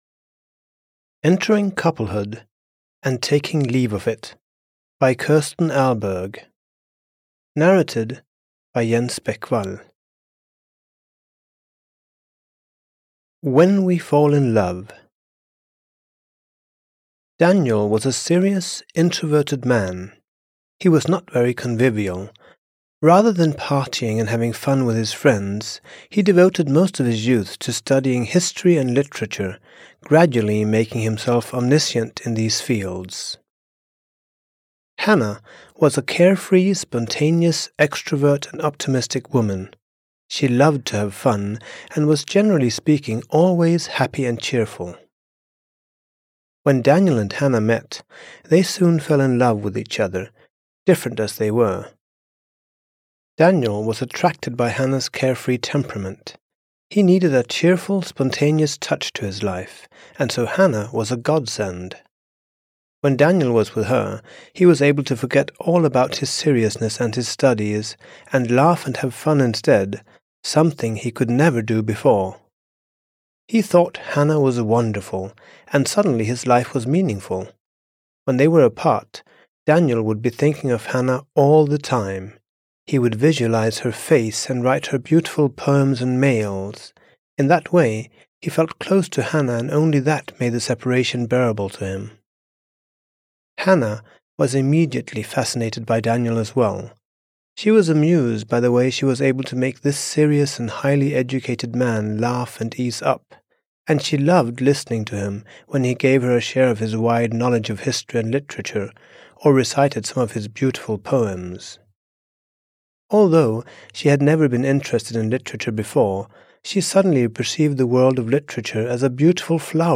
Entering Couplehood...and Taking Leave of It (EN) audiokniha
Ukázka z knihy